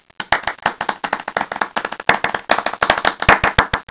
clap.wav